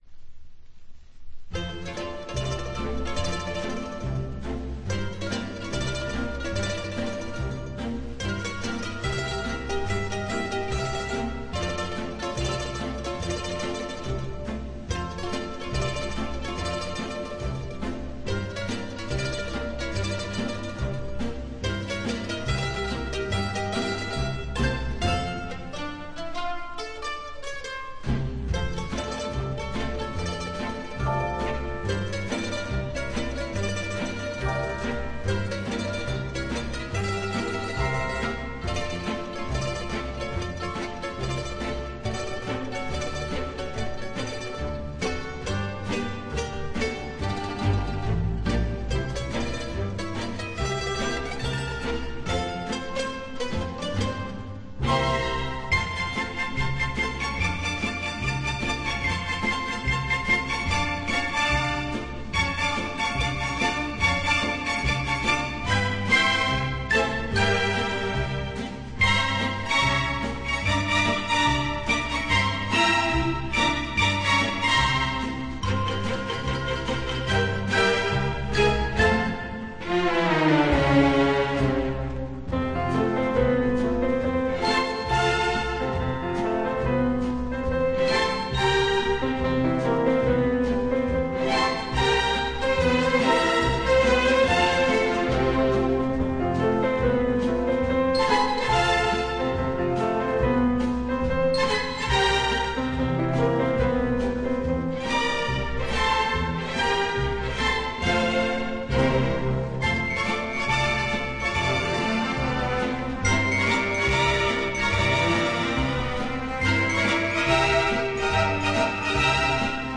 Жанр: Pop, Easy Listening